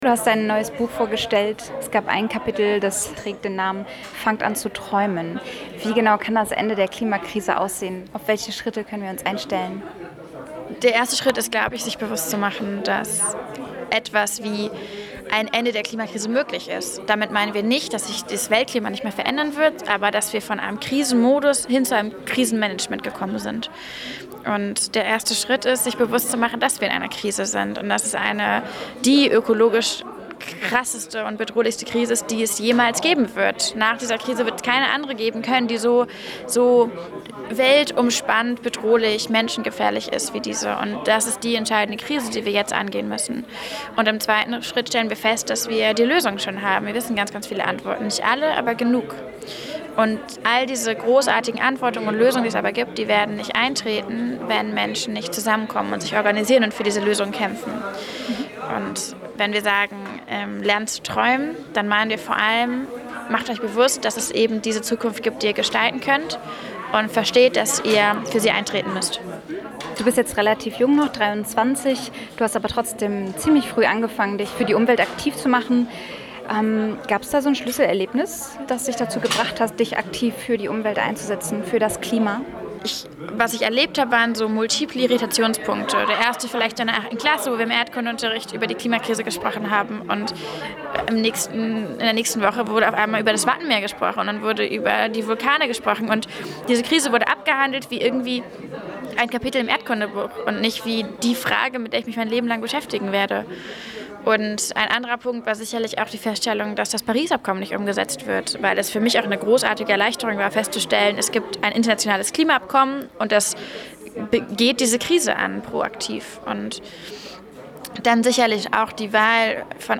Interview mit der Klimaaktivistin Luisa Neubauer